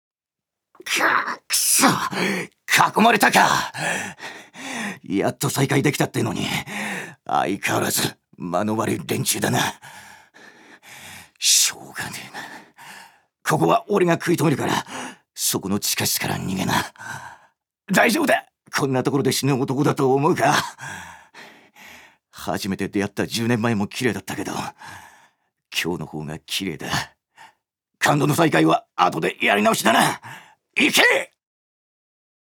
預かり：男性
セリフ１